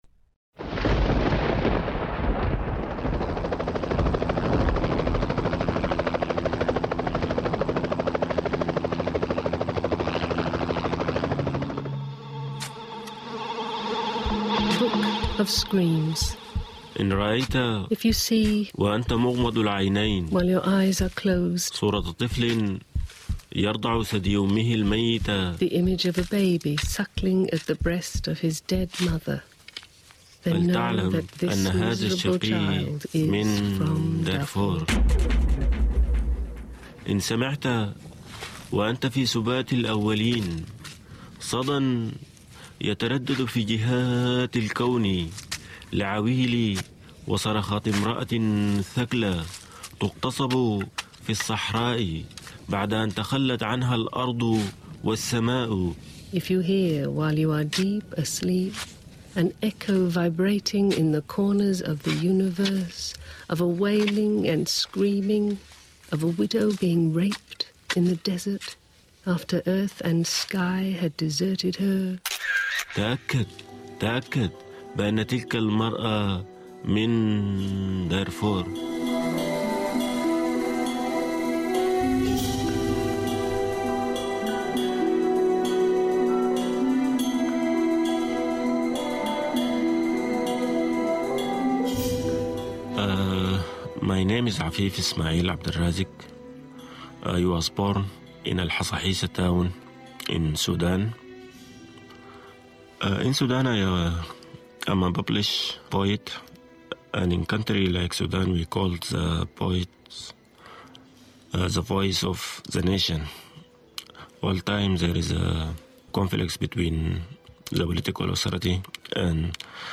Below is an excerpt from a collaborative radio piece I produced as part of the cultural development program of the Sydney based Information and Cultural Exchange (ICE) and the ABC’s Poetica program on Radio National.